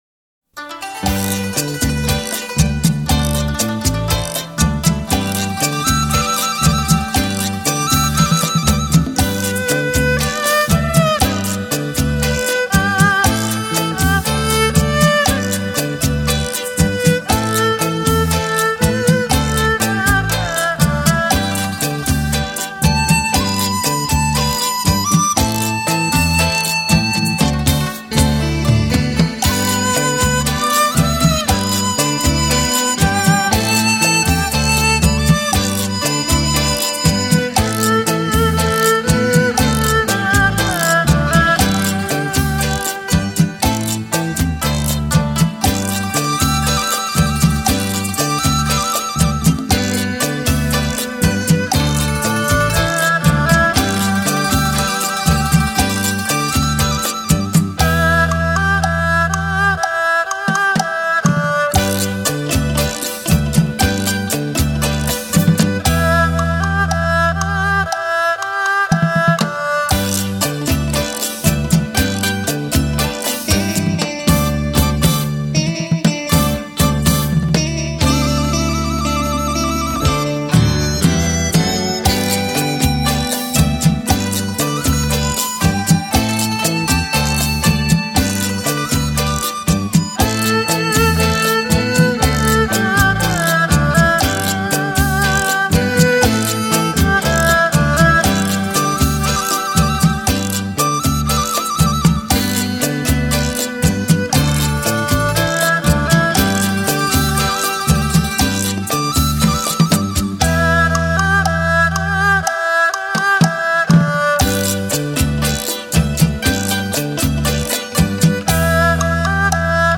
Cha Cha